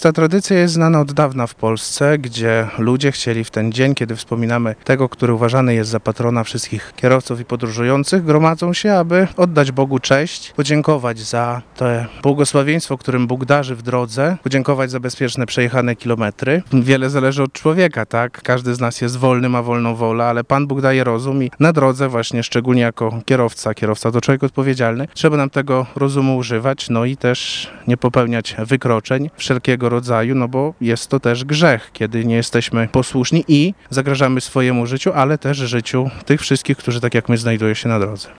ksiadz-o-odpowiedzialnosci-na-drodze.mp3